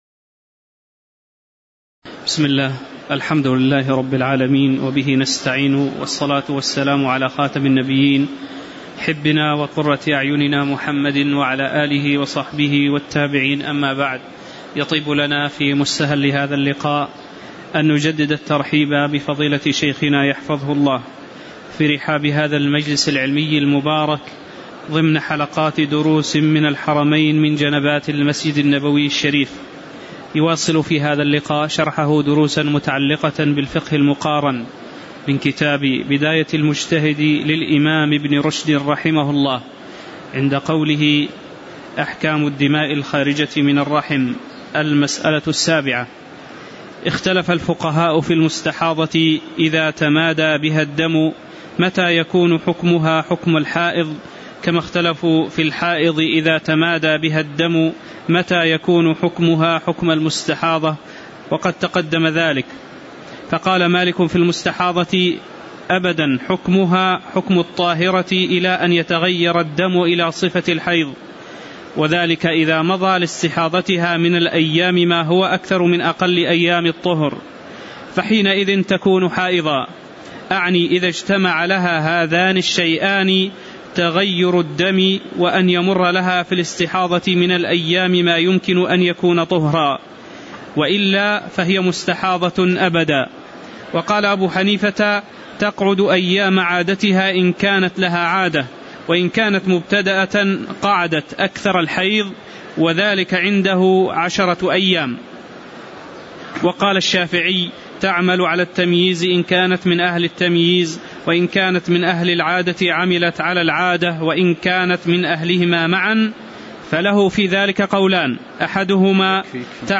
تاريخ النشر ٢٢ جمادى الأولى ١٤٤٠ هـ المكان: المسجد النبوي الشيخ